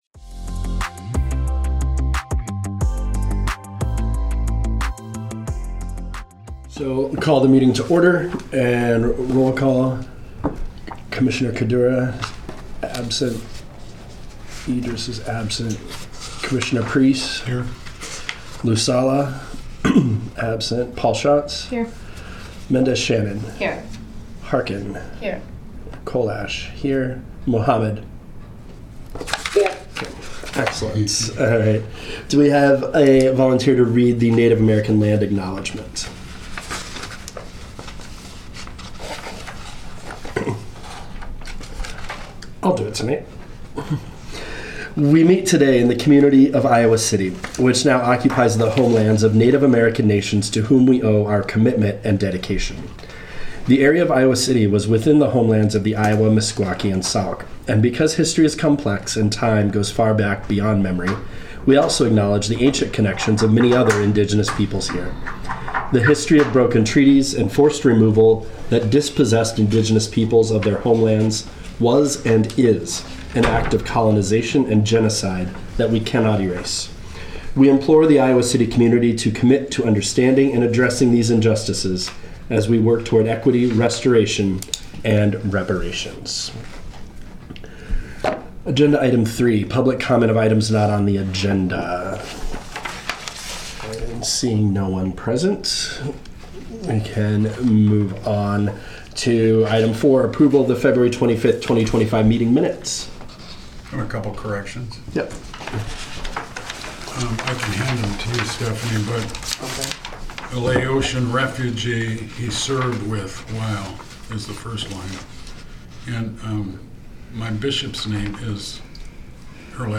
Regular monthly meeting of the Human Rights Commission.